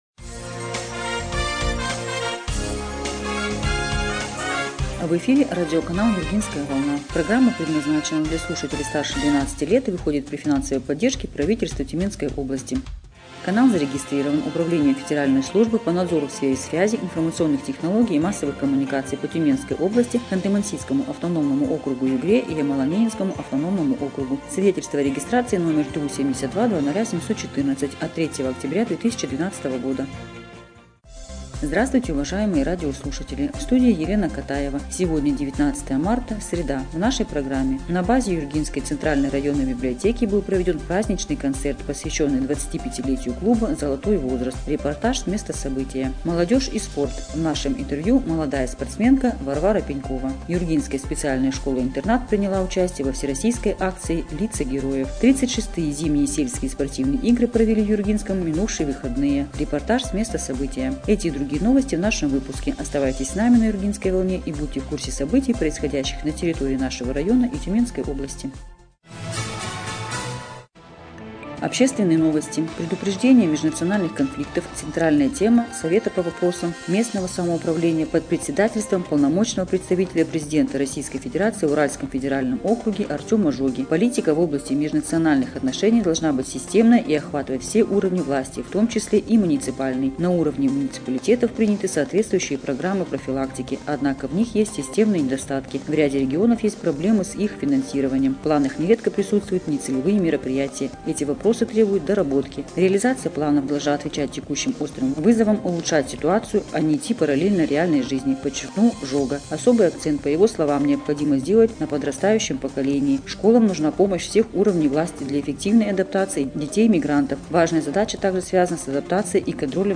Эфир радиопрограммы "Юргинская волна" от 19 марта 2025 года